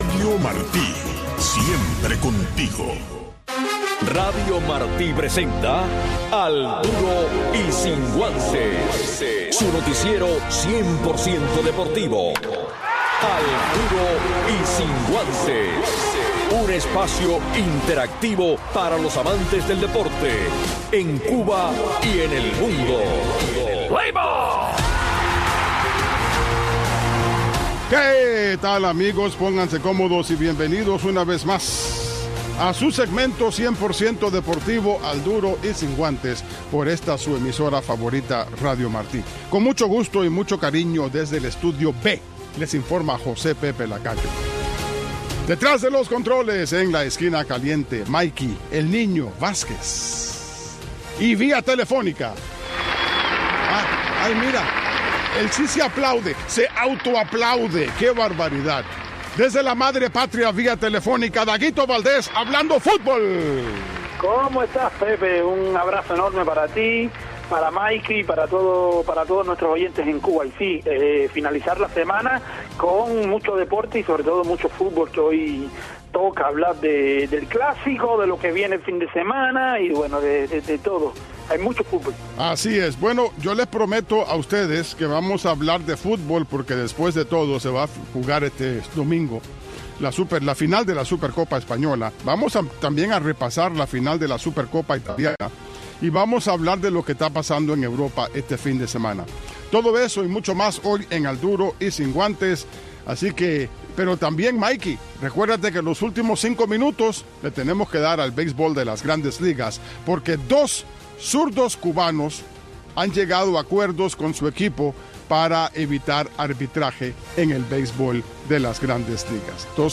Un resumen deportivo en 60 minutos conducido por